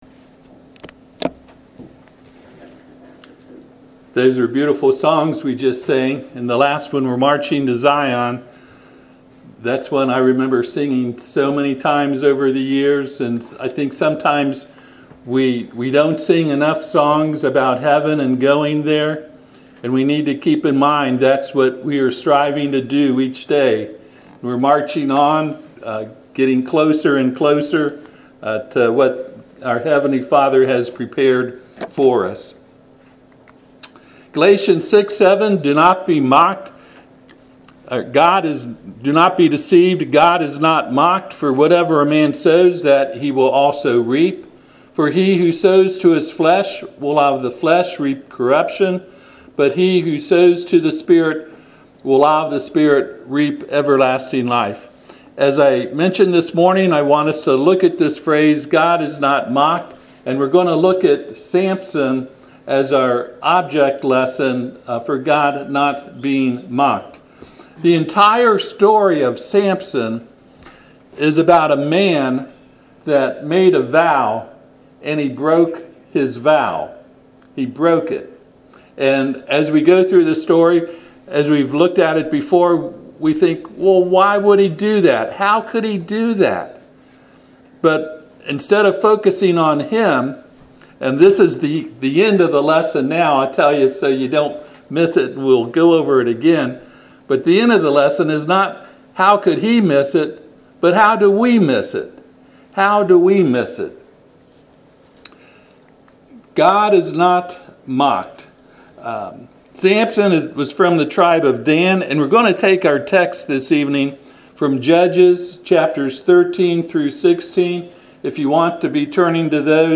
Passage: Judges 13-16 Service Type: Sunday PM